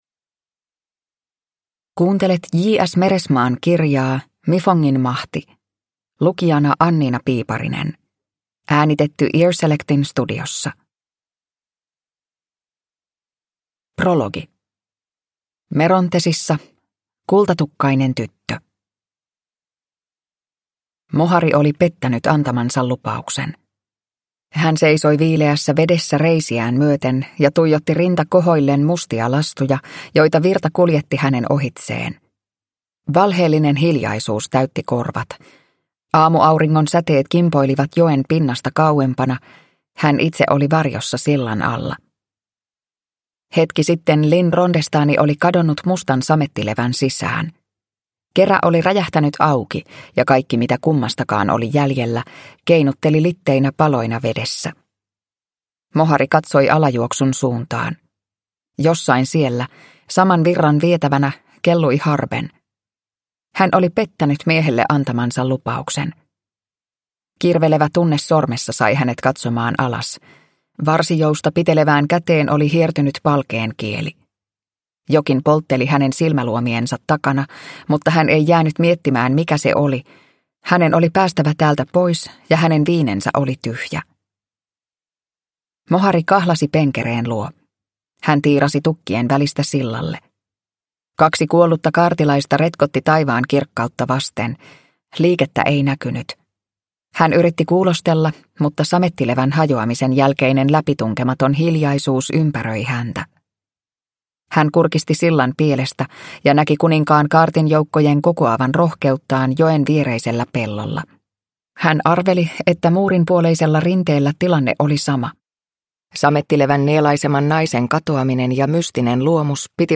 Mifongin mahti – Ljudbok